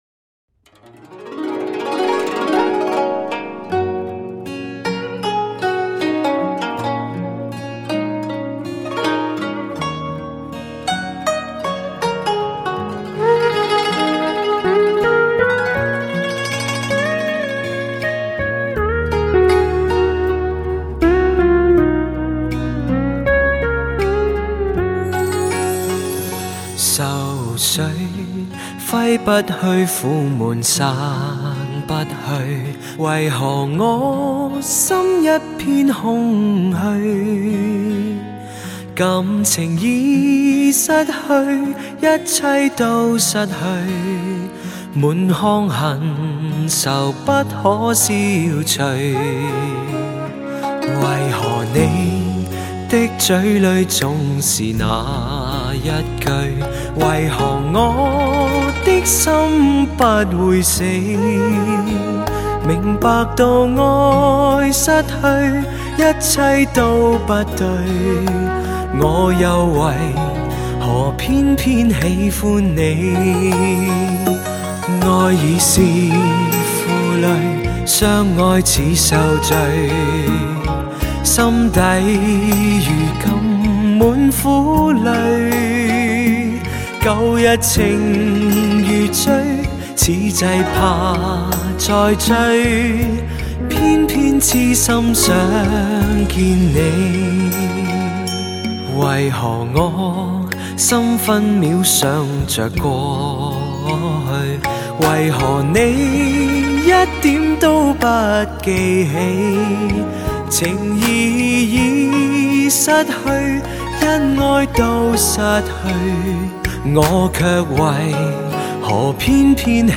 母带级音质 发烧新体验
全面恢复黑胶唱片的空气感和密度感
低音强劲有力，中音清晰丰满，高音柔和圆润，精确的乐器定位，清晰的人声，层次分明，声场辽阔。